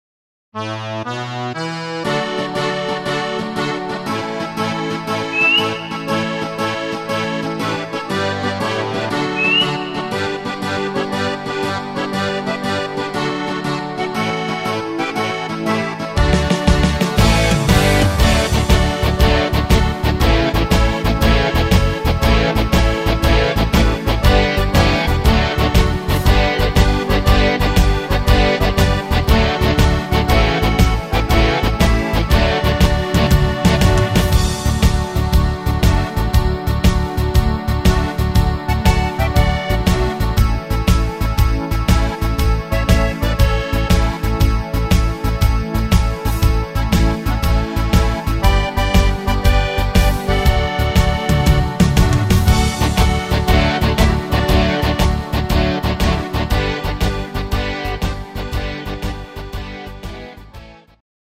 Rhythmus  Alpen Rock
Art  Volkstümlich, Deutsch, Alpenfetzer